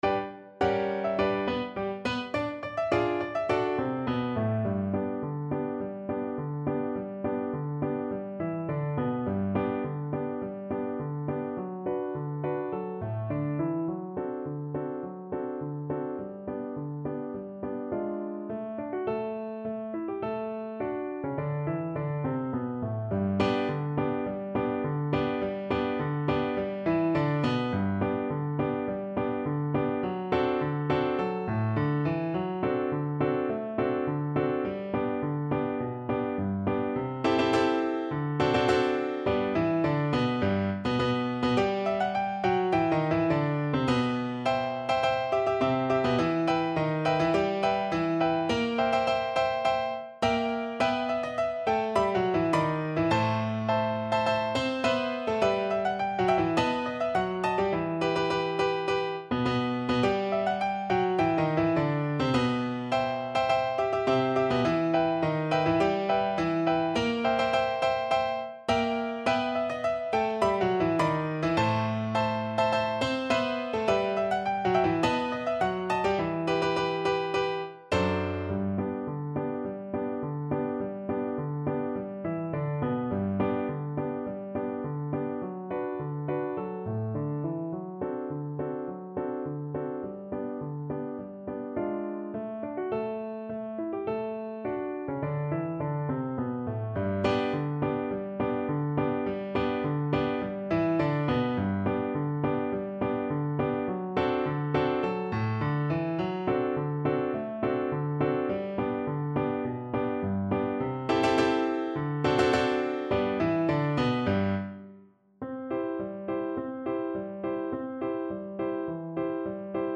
2/2 (View more 2/2 Music)
Quick March = c.104